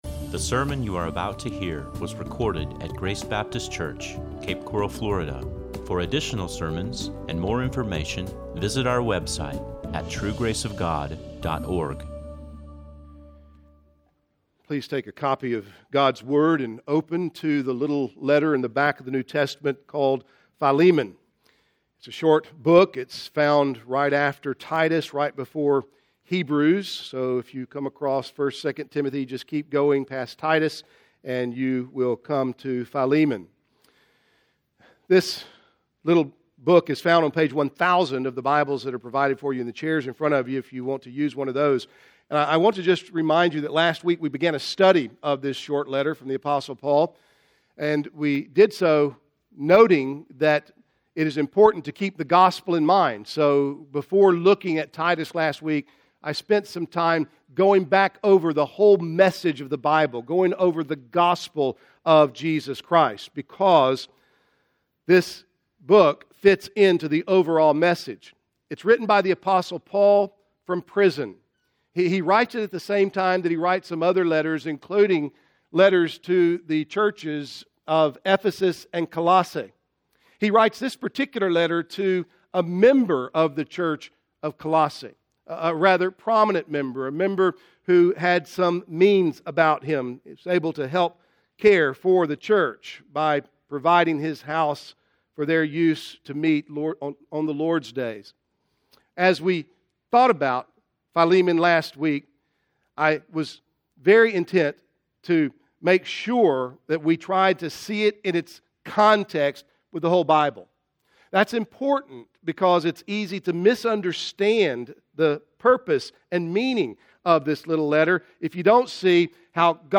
This is a sermon on Philemon 1:1-25.